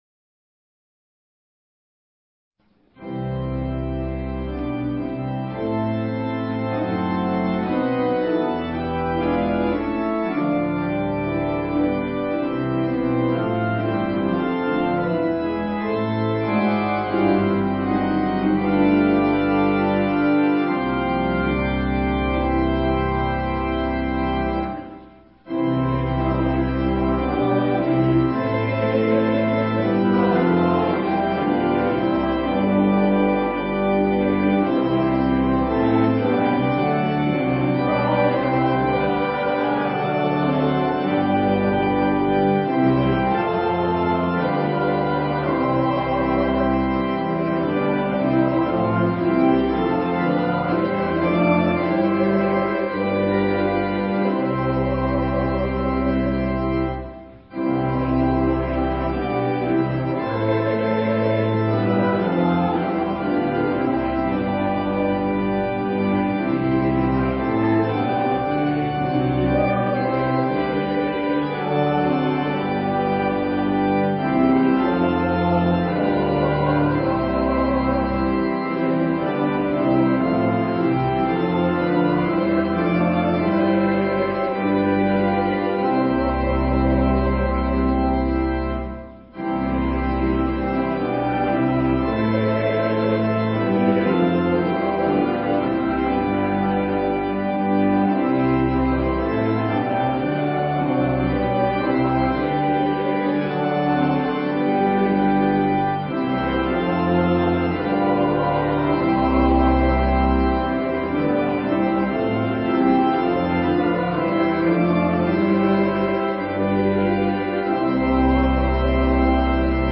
The services we post here were preached the previous week.